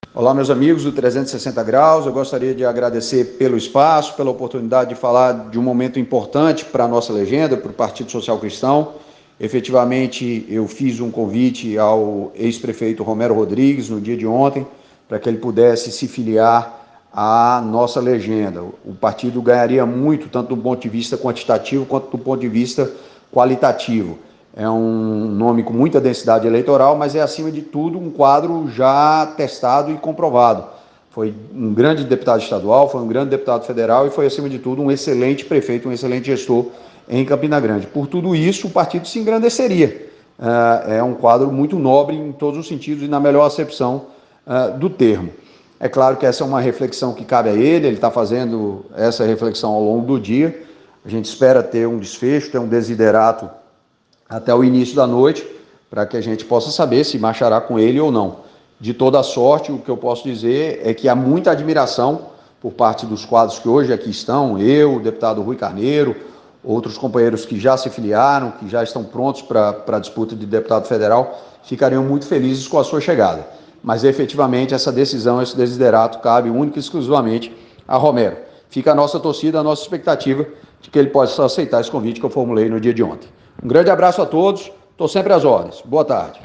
O deputado federal Leonardo Gadelha, presidente do PSC na Paraíba, confirmou ao programa 360 Graus desta quarta-feira (30) que formalizou convite, na terça (29), para que o ex-prefeito de Campina Grande, Romero Rodrigues, se filiasse ao partido.